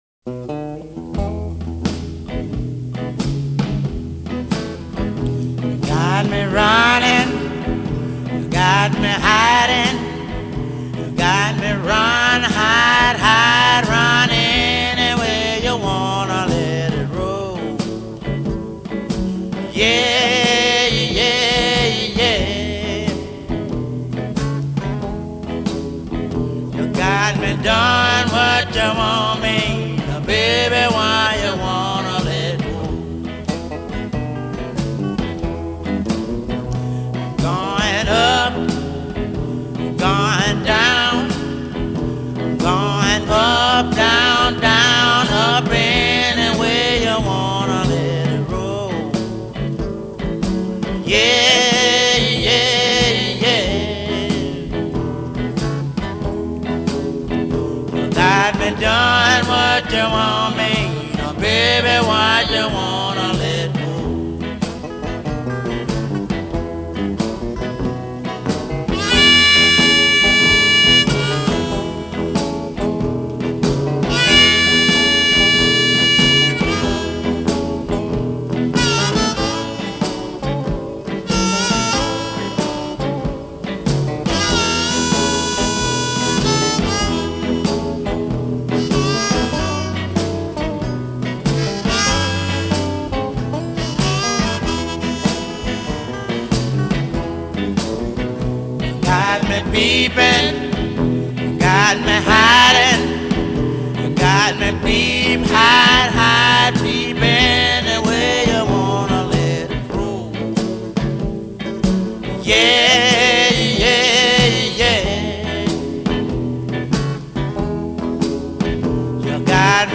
blues guitarist